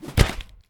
melee-hit-2.ogg